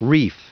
Prononciation du mot reef en anglais (fichier audio)
Prononciation du mot : reef